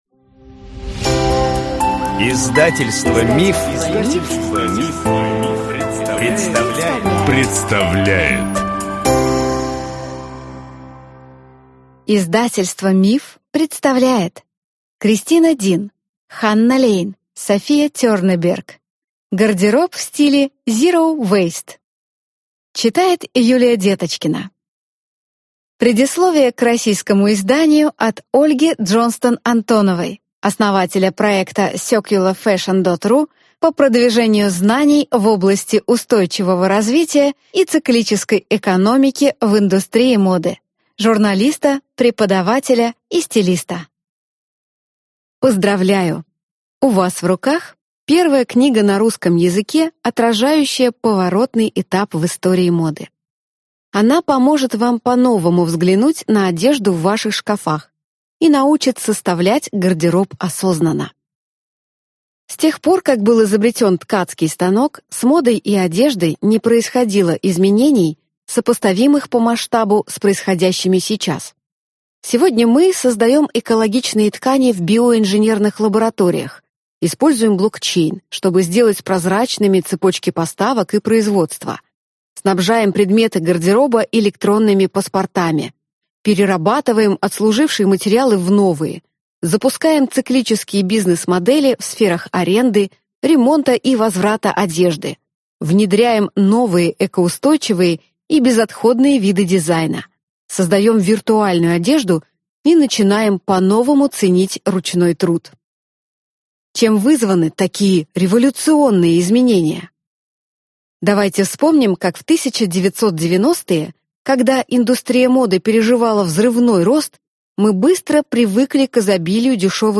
Аудиокнига Гардероб в стиле Zero Waste | Библиотека аудиокниг